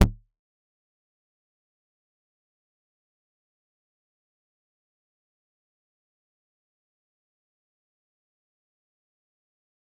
G_Kalimba-G0-f.wav